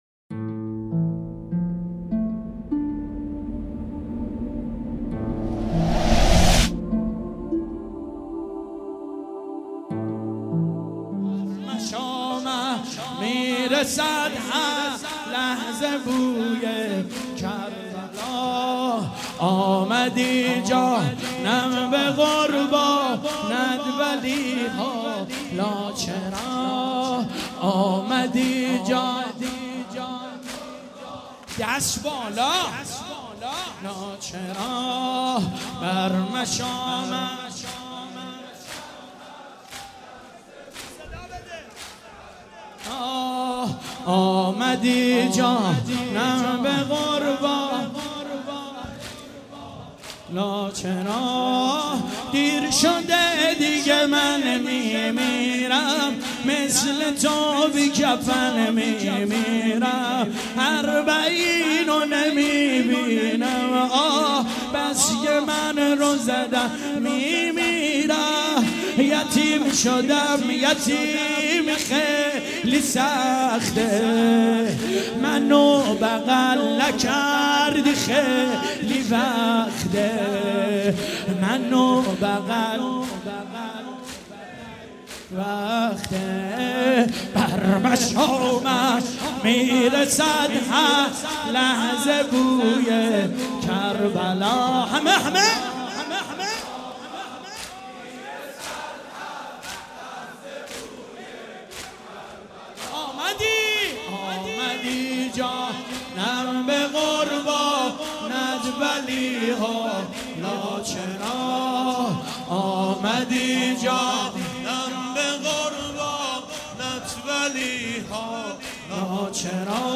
زمینــه | شب سوم محرم 1397 | هیأت غریب مدینه